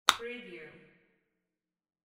Handbag lock sound effect .wav #4
Description: The sound of a handbag lock snapping open or close (a single click)
Properties: 48.000 kHz 16-bit Stereo
Keywords: handbag, purse, bag, lock, locking, unlocking, snap, click, open, opening, close, closing, shut, shutting, latch, unlatch
handbag-lock-preview-4.mp3